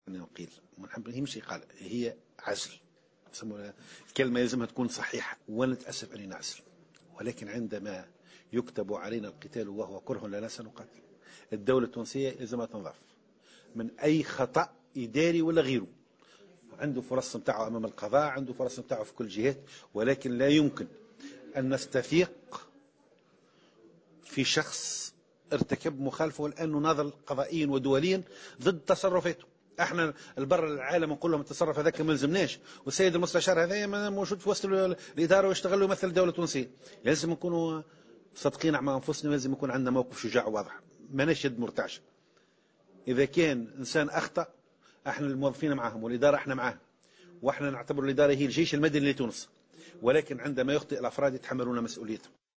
وأضاف كورشيد في تصريح لمراسلة "الجوهرة أف أم" خلال زيارته لولاية نابل أنه عندما يخطئ الأفراد يجب أن يتحملوا مسؤولياتهم، مؤكدا أنه لا يمكن أن نعطي الثقة مجددا في شخص ارتكب مثل هذا التجاوز، وفق تعبيره.